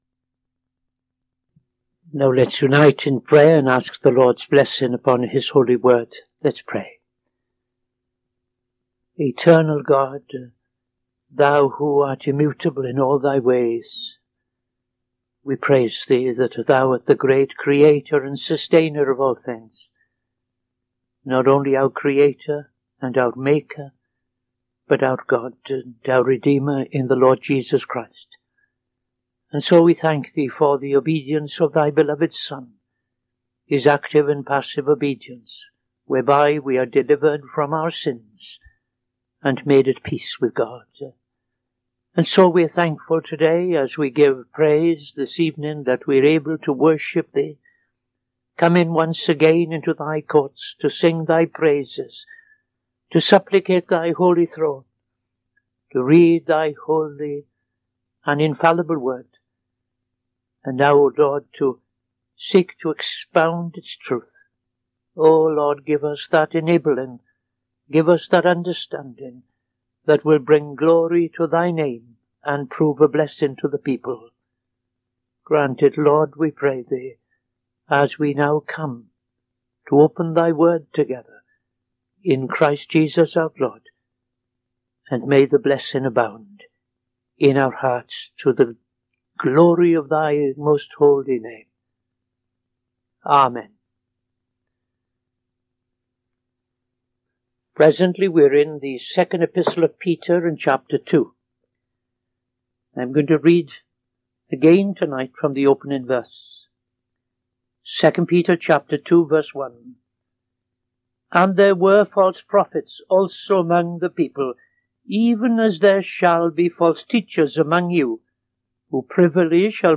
Opening Prayer and Reading II Peter 2:1-9